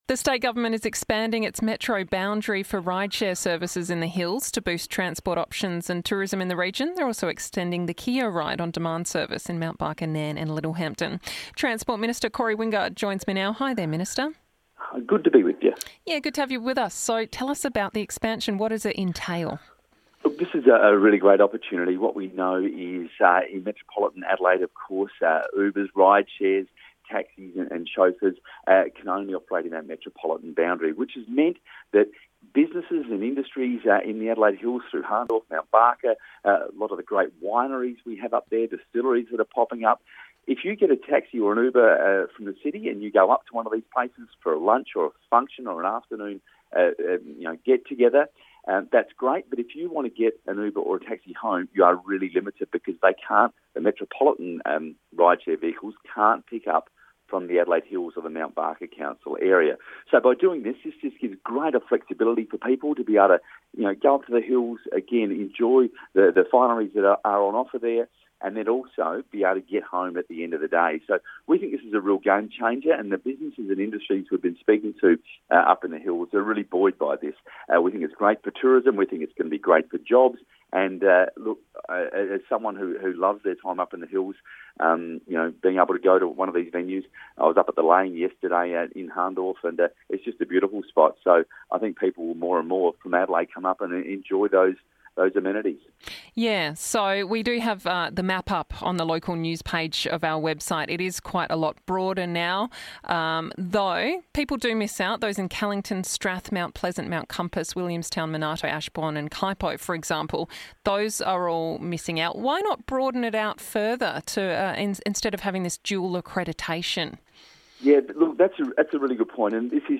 1392: Transport Minister Talks Rideshare Expansion